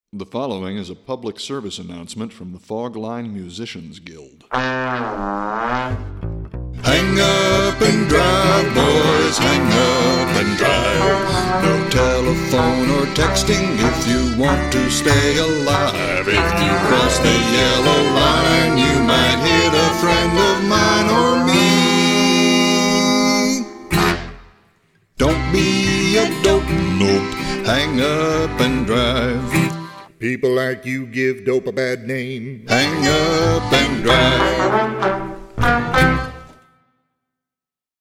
vocals, guitar
trombone
button accordion
bass
mandolin